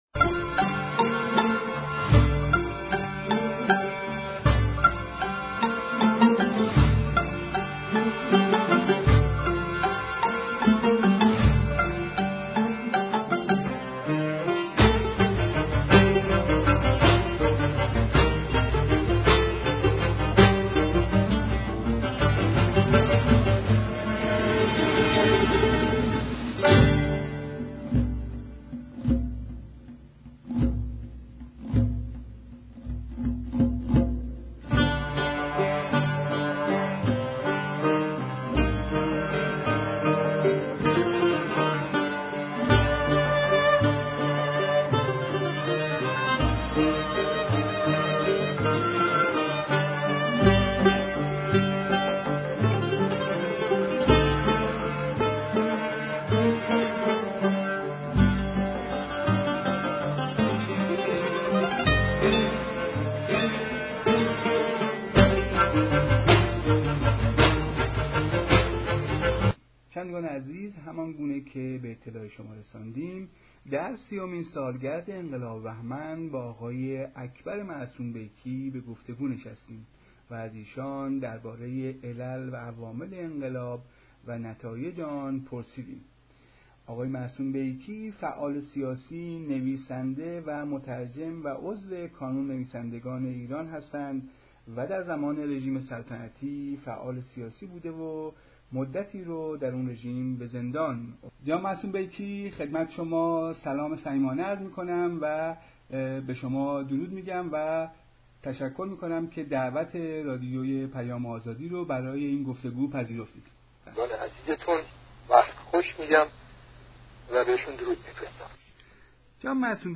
دموکراسی بدون سوسیالیسم، دموکراسی نیست و سوسیالیسم بدون دموکراسی سوسیالیسم نیست . گفتگوی رادیو پیام آزادی